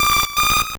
Cri d'Héliatronc dans Pokémon Or et Argent.